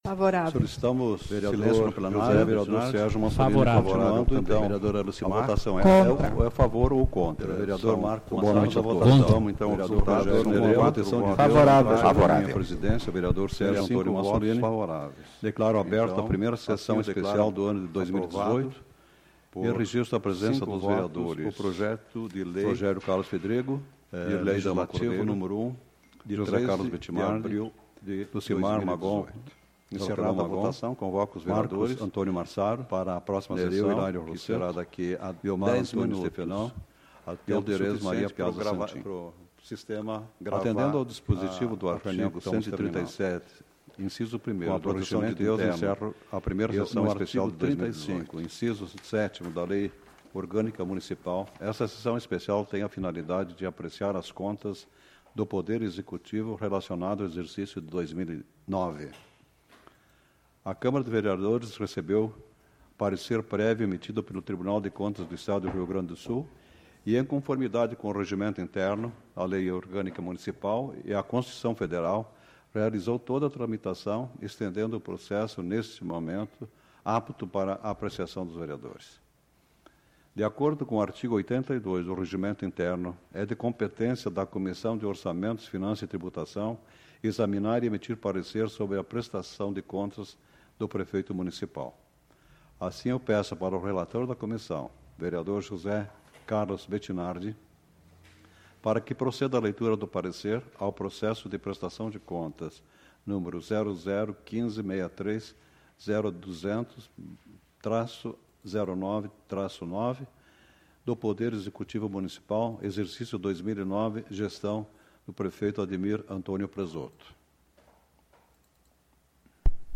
Tipo de Sessão: Especial